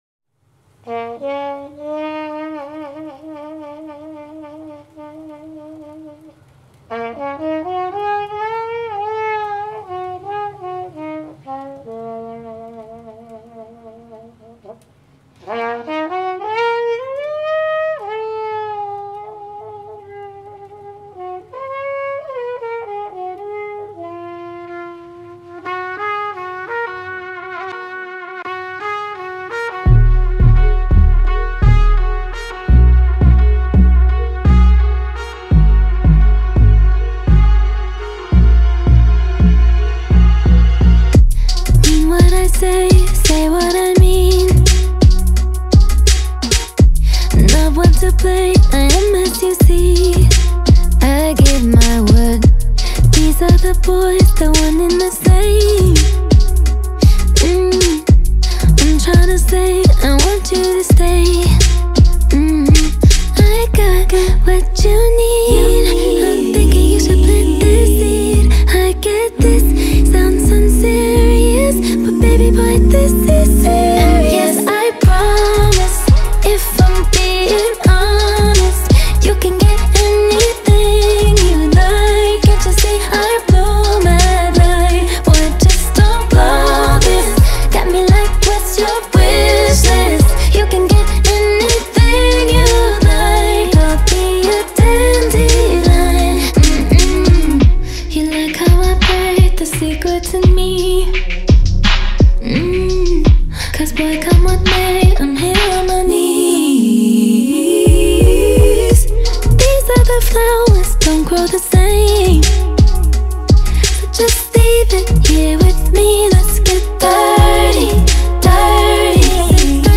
این ترک در سبک پاپ خونده شده